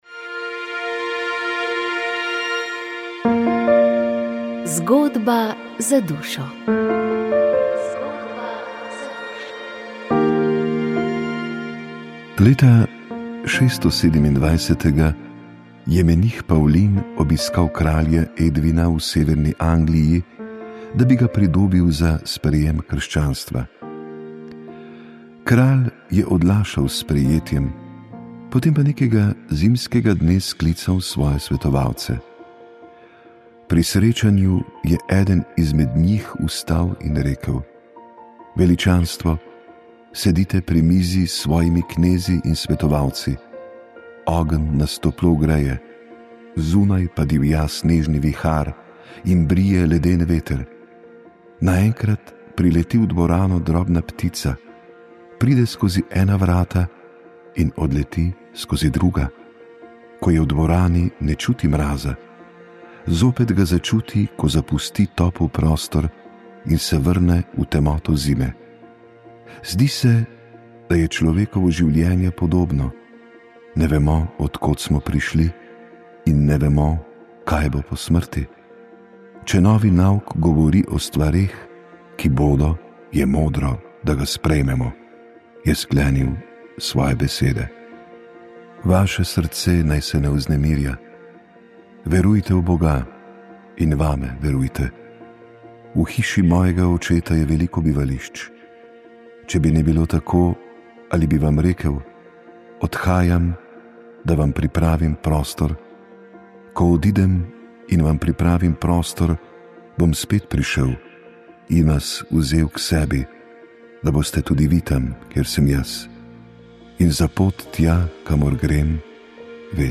Reportaža z romanja po Italiji
V tokratni oddaji ste slišali reportažo z romanja po Italiji, ki smo ga pripravili od 11. do 14. maja.